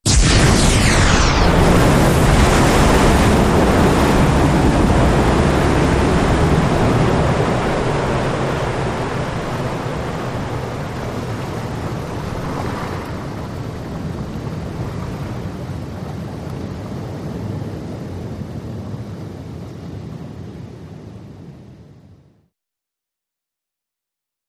Depth Charge
Explosion, Surface Perspective Depth Charge Multiple Ver. 0